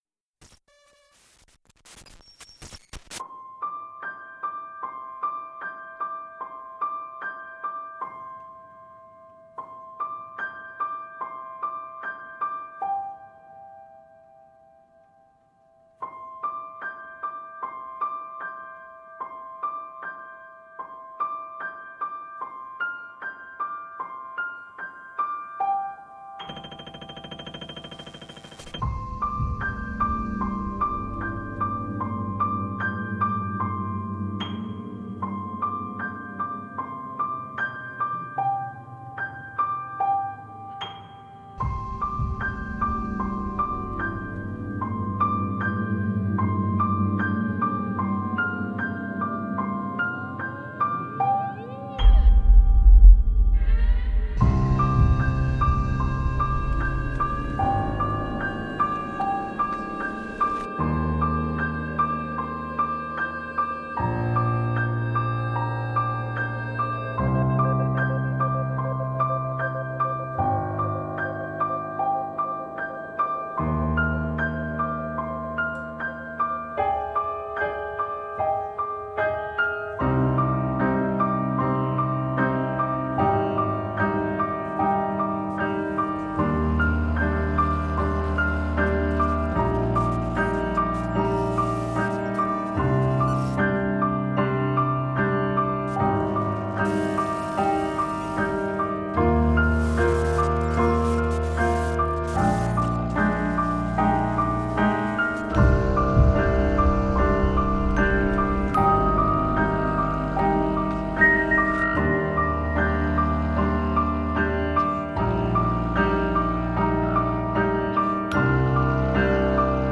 2 pianos